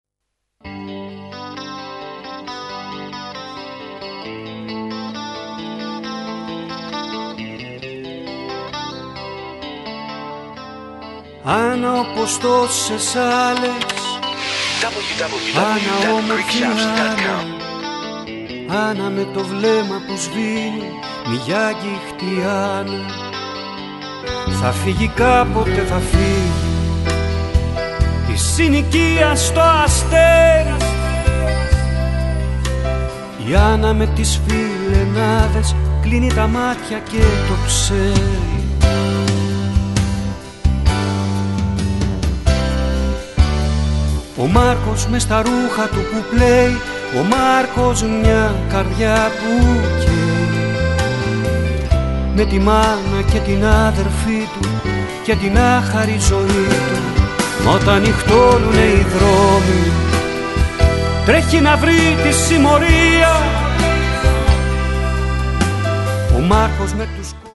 all of best hits by the top-selling light rock duo